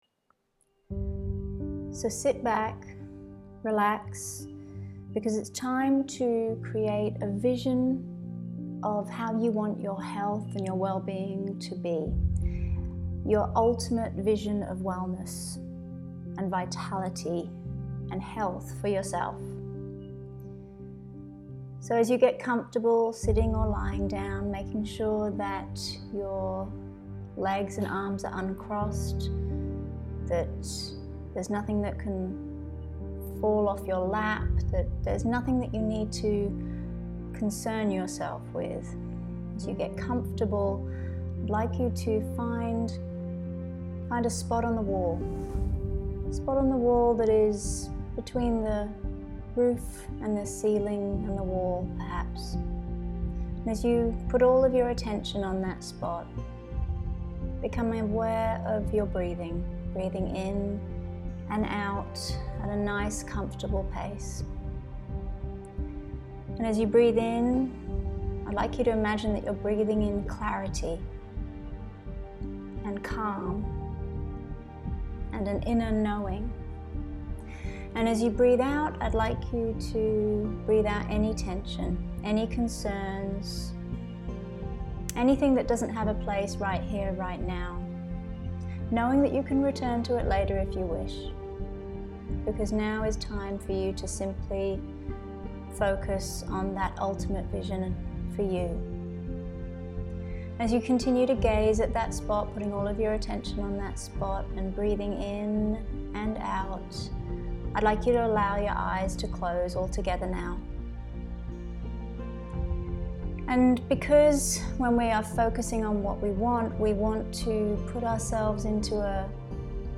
Audio Resources Patients receive access to a wide range of downloadable audio resources including meditations, visualisations and mindset recordings. Click on a sample audio resource below (Guided Practice - 'Creating Your Wellness Vision'):